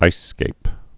(īsskāp)